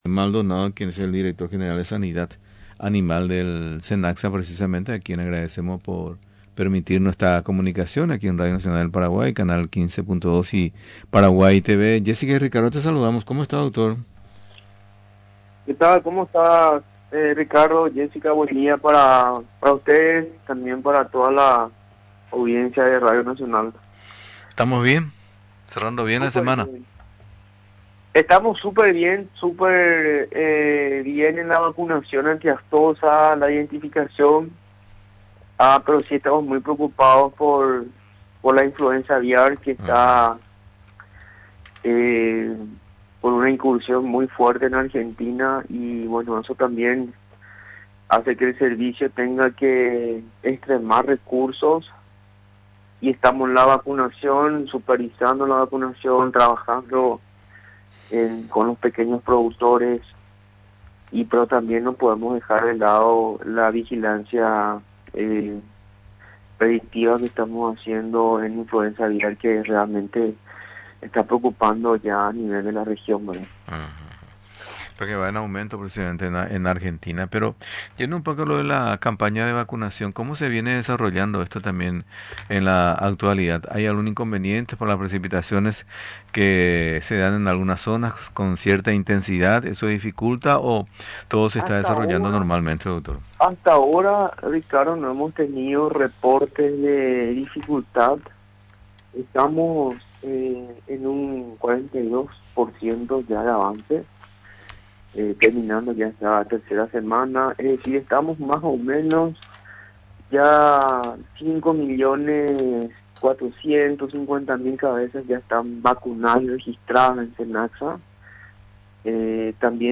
Continúan los trabajos de inmunización el marco del Plan de vacunación estratégica contra la Brucelosis Bovina, informó este viernes el director general de Sanidad Animal del Servicio Nacional de Calidad y Salud Animal, Víctor Maldonado.
Recordó, durante la entrevista en Radio Nacional del Paraguay, que en estos últimos días, la tarea se desarrolló de manera conjunta con las Asociaciones de productores lecheros de Maracaná, Departamento de Canindeyú.